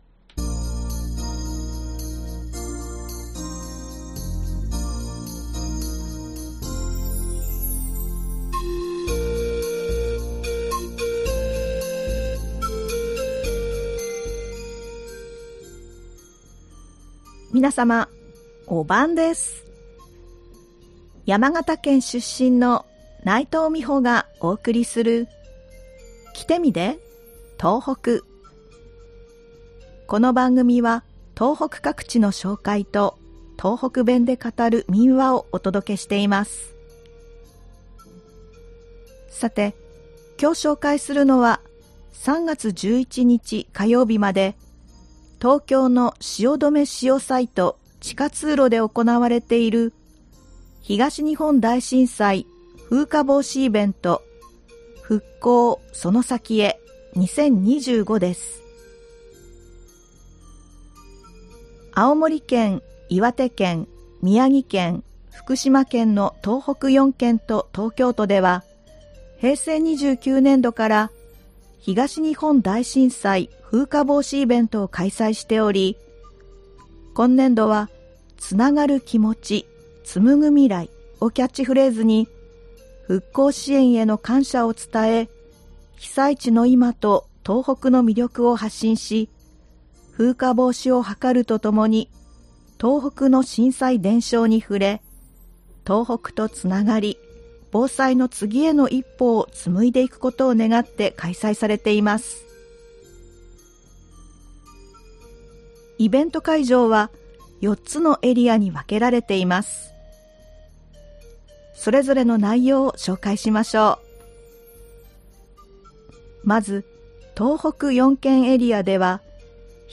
この番組は東北各地の紹介と、東北弁で語る民話をお届けしています。